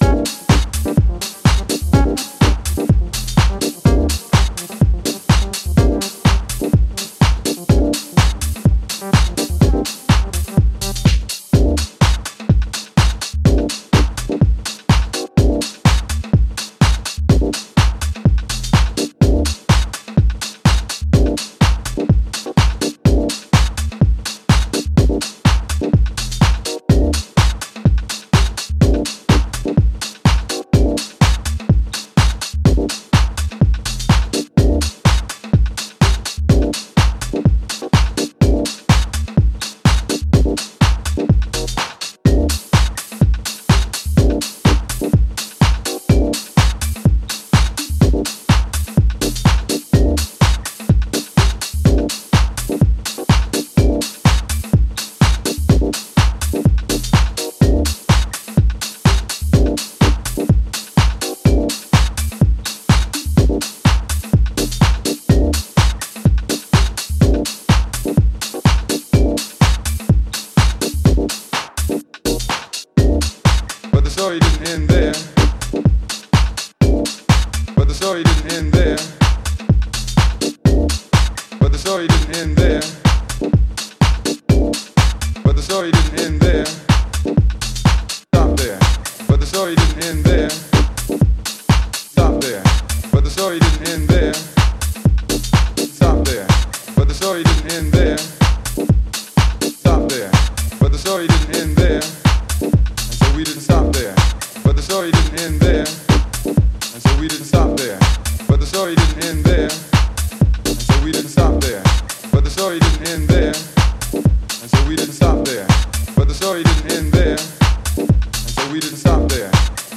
House reveals again its glorious aesthetic splendor.